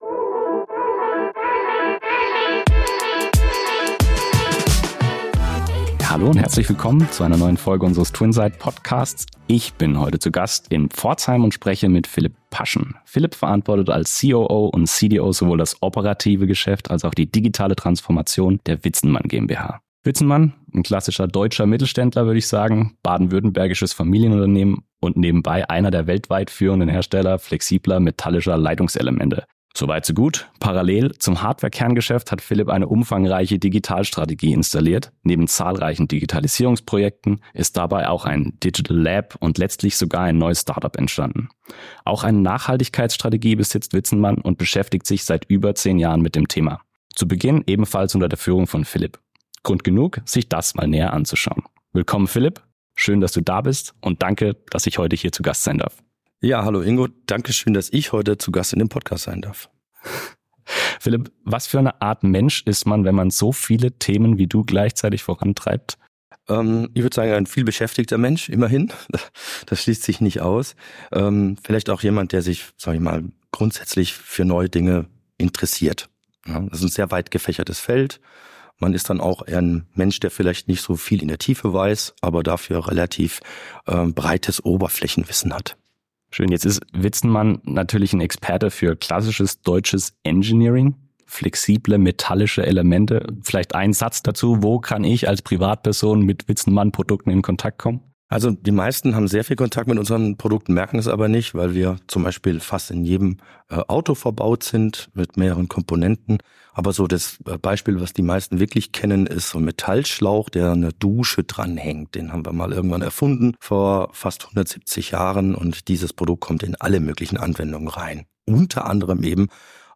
Interview ~ TwinSights Podcast – Einblicke in die digitale und nachhaltige Transformation